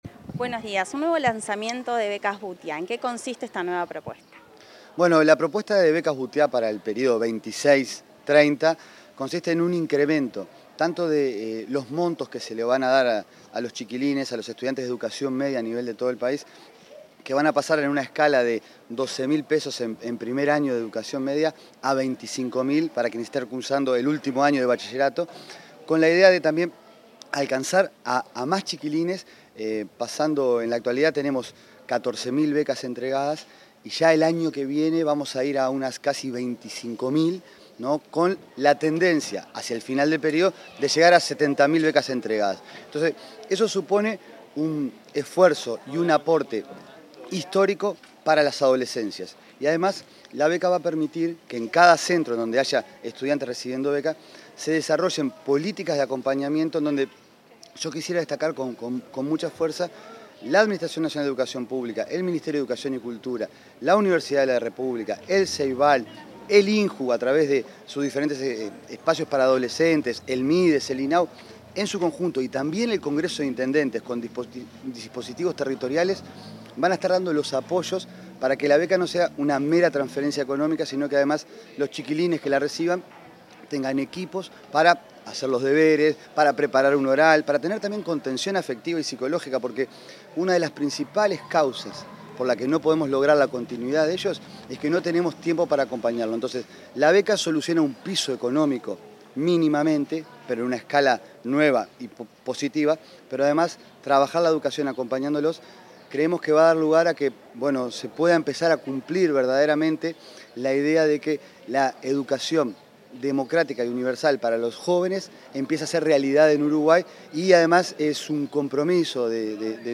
Declaraciones del director nacional de Educación, Gabriel Quirici
Durante el lanzamiento de la edición 20226 de las becas Butiá, el director nacional de Educación, Gabriel Quirici, detalló el alcance de las becas.